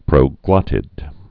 (prō-glŏtĭd) also pro·glot·tis (-glŏtĭs)